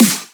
Breakn' A Sweat_Snare.wav